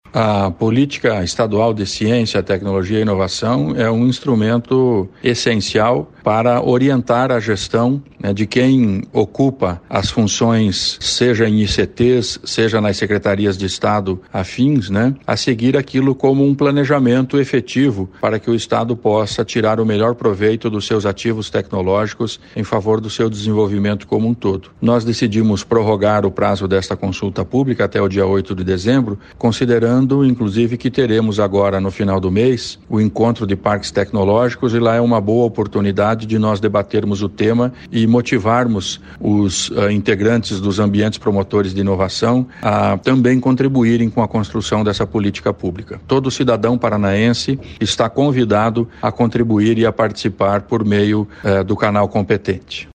Sonora do secretário da Ciência, Tecnologia e Ensino Superior, Aldo Bona, sobre a prorrogação da consulta pública para política de ciência, tecnologia e inovação